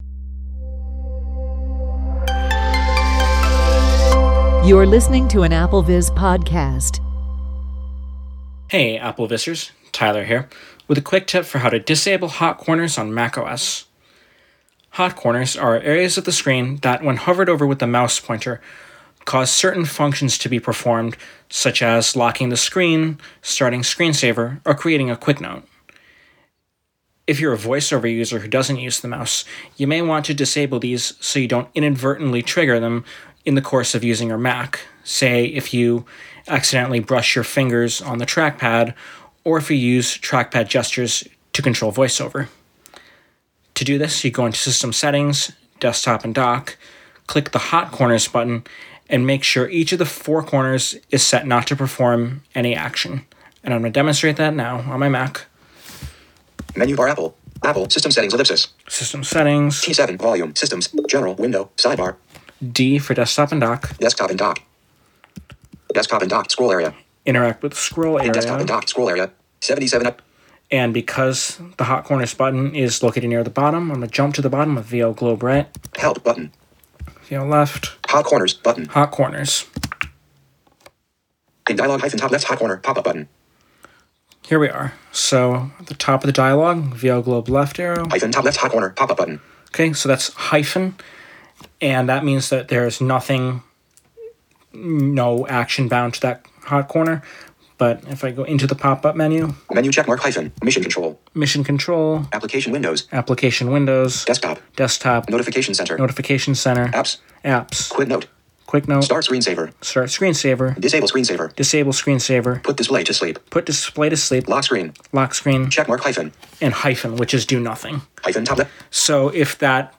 Walk-through